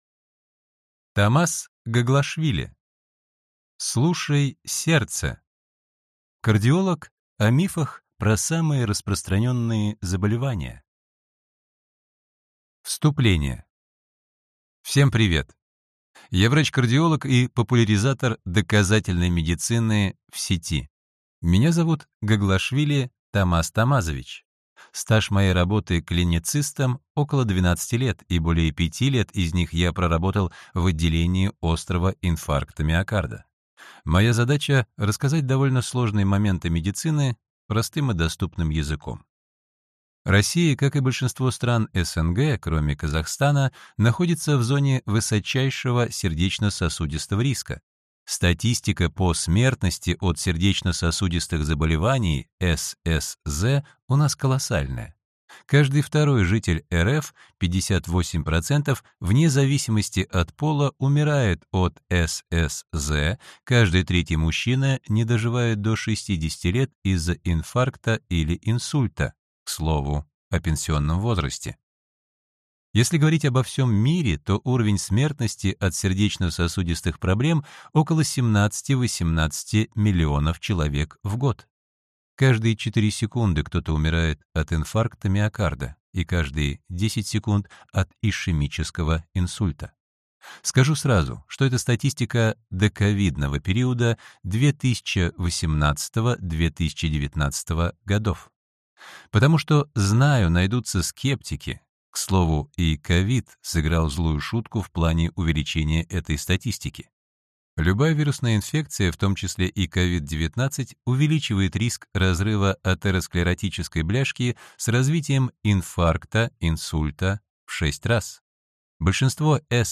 Аудиокнига Слушай сердце. Кардиолог о мифах про самые распространенные заболевания | Библиотека аудиокниг